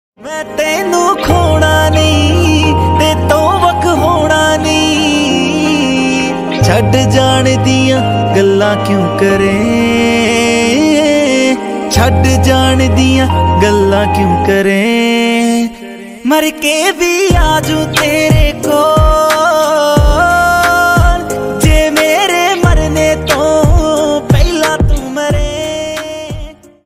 Romantic song ringtone